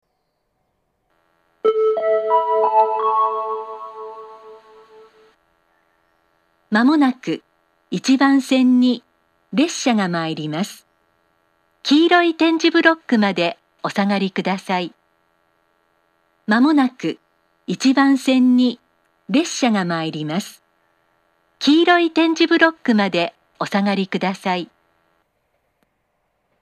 ２０１９年１２月１６日には放送装置が更新され、発車メロディーの音質が向上し、自動放送が巌根・館山型に変更されました。
１番線接近放送
fusa1bansen-sekkin2.mp3